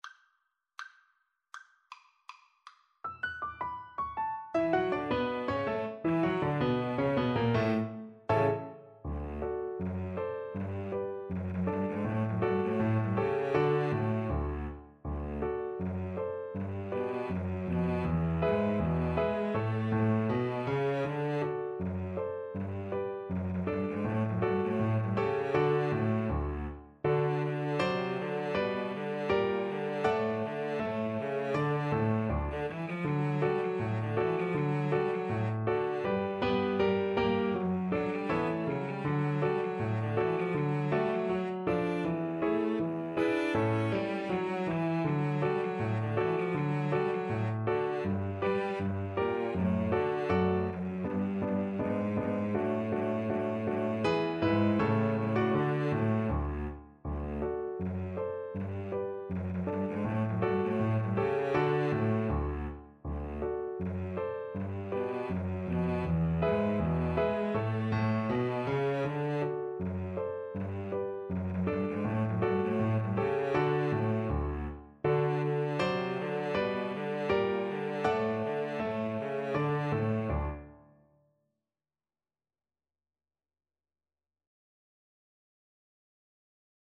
Cello 1Cello 2Piano
D major (Sounding Pitch) (View more D major Music for Cello Duet )
Presto (View more music marked Presto)
Jazz (View more Jazz Cello Duet Music)